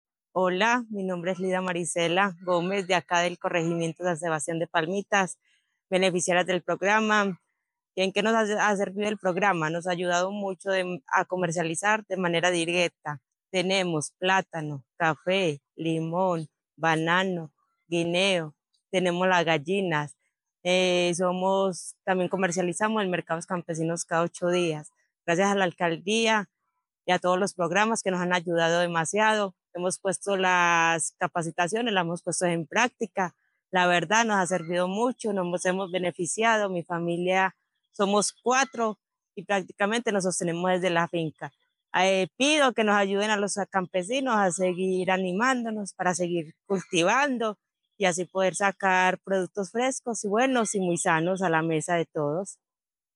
Declaraciones de beneficiarios
beneficiaria